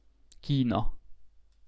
Ääntäminen
US : IPA : [ˈtʃaɪ.nə]